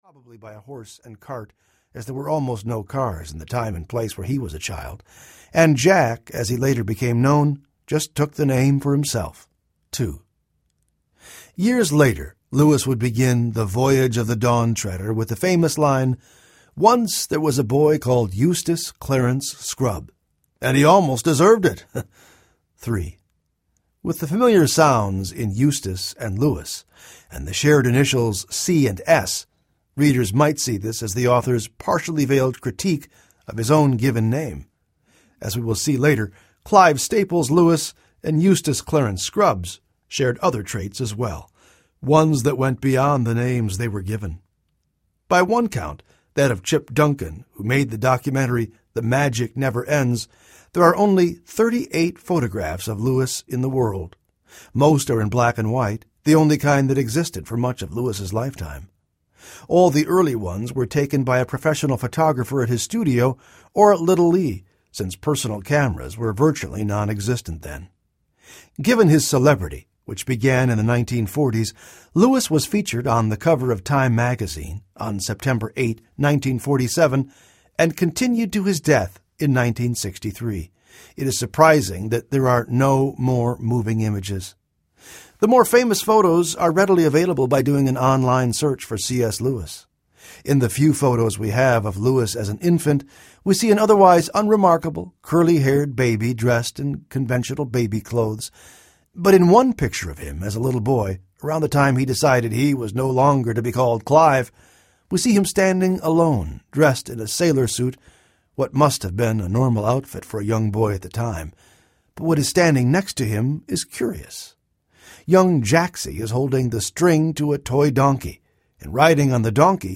A Life Observed Audiobook
7.7 Hrs. – Unabridged